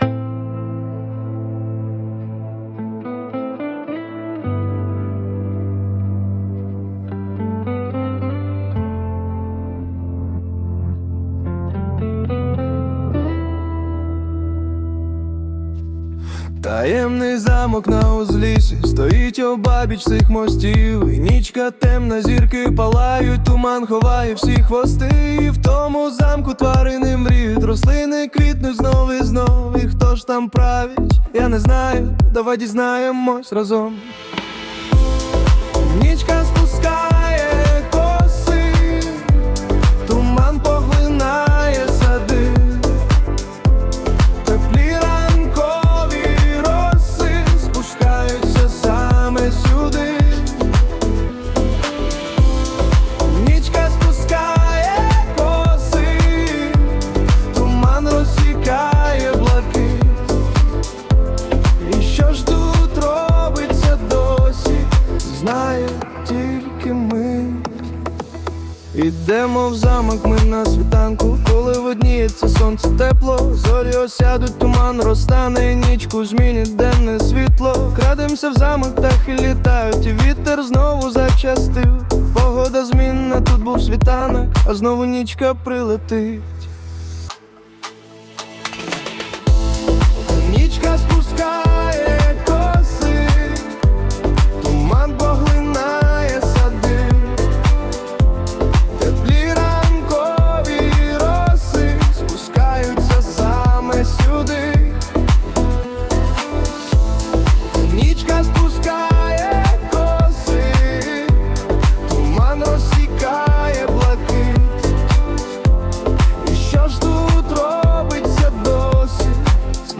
Стиль: Поп, реп